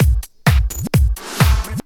Electrohouse Loop 128 BPM (11).wav